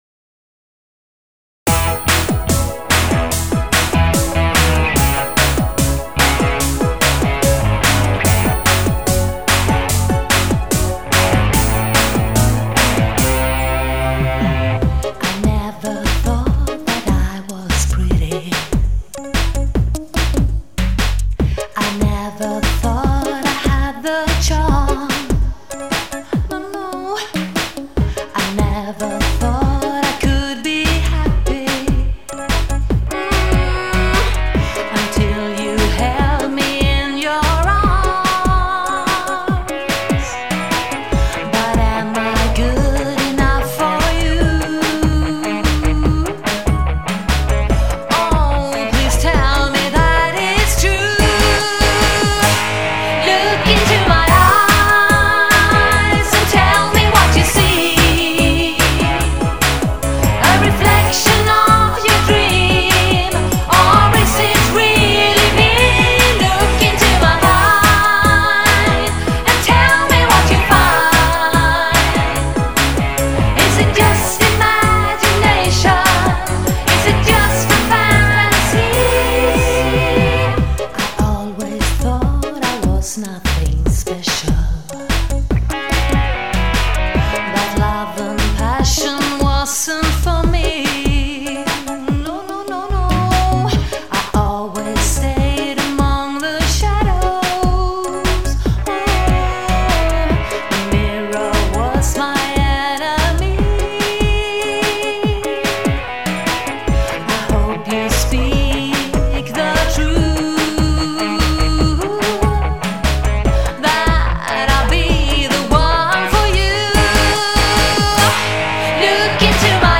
Have a taste of my schlager cake: